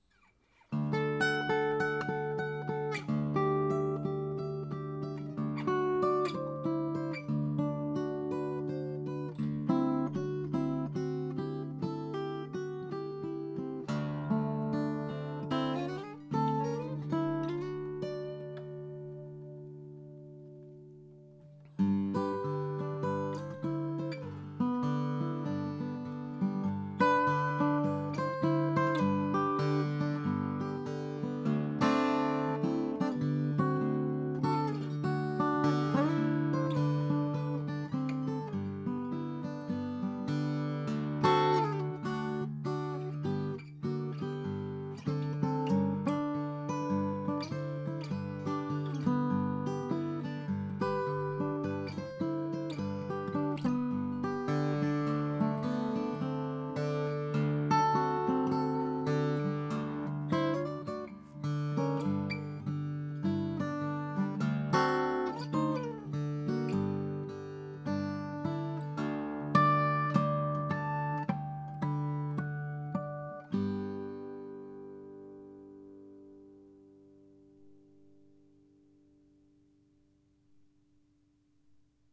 Вот сравнение SM58 с KSM141, тот же источник, та же позиция микрофона , уровень соответствует:
SM58
Я, конечно, не думаю, что это звучит одинаково, но я думаю, что они звучат намного более похожи, чем большинство людей сообщают о своих динамических и конденсаторных звуках экспериментов.
SM58.wav